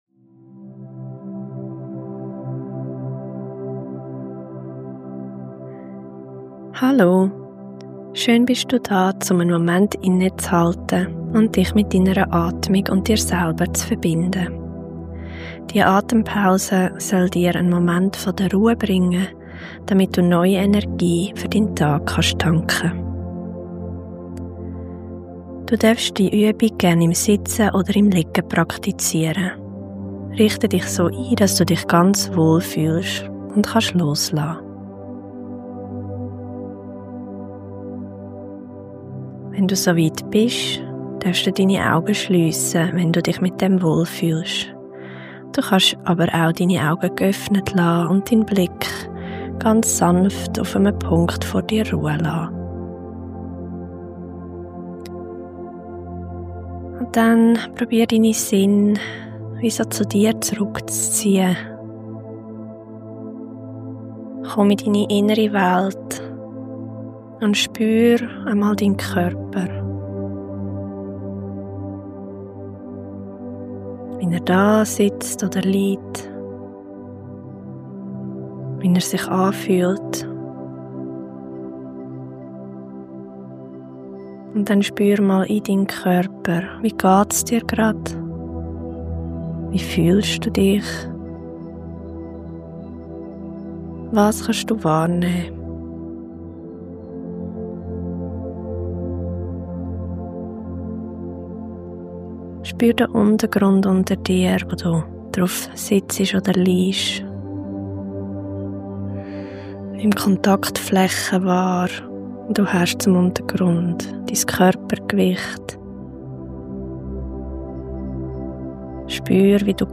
Ich führe dich durch eine Atemmeditaiton, die dich zur Ruhe kommen lässt, dein Nervensystem reguliert und dir neue Energie schenkt.